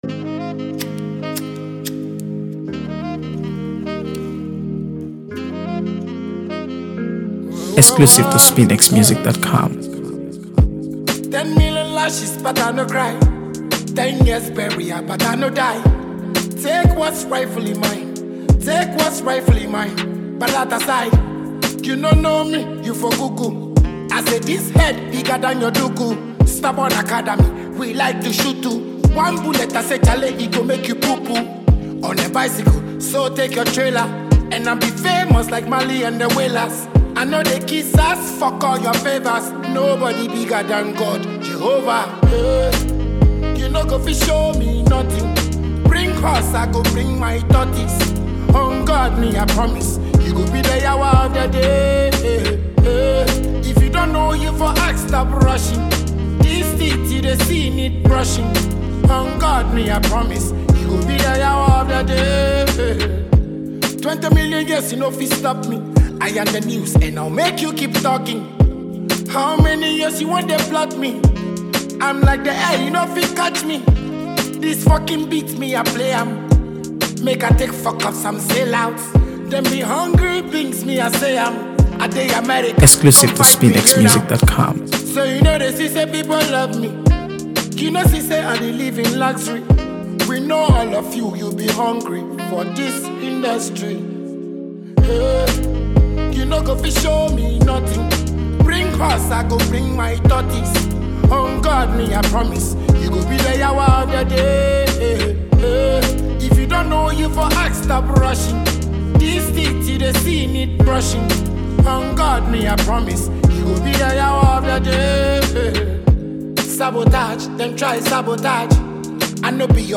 AfroBeats | AfroBeats songs
Known for his bold lyrics and energetic style
unique blend of dancehall and Afrobeats